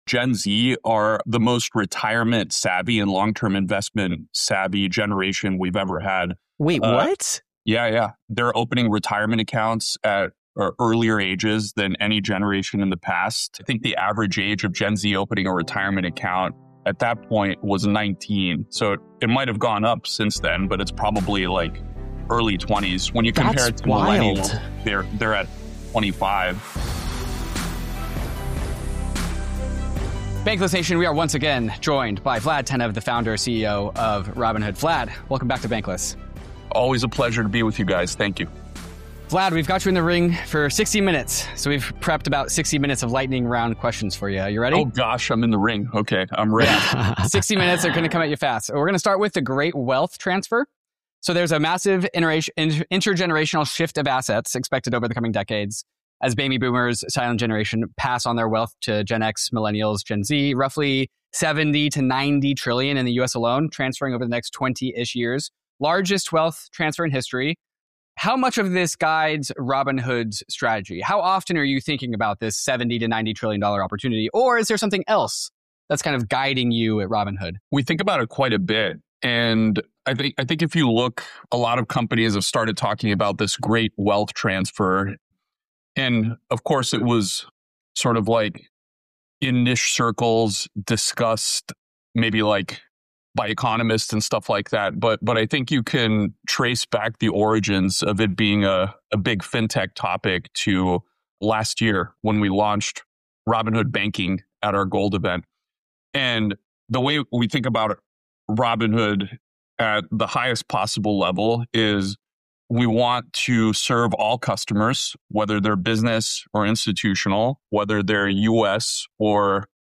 It may be over where the $90 trillion great wealth transfer actually lands. In this episode, Robinhood founder and CEO Vlad Tenev returns to Bankless for a fast-moving conversation on how Robinhood wants to position itself for that generational handoff, why he believes 24/7 markets and tokenization are inevitable, and why the popular narrative around Gen Z as financially reckless misses what he’s seeing on-platform.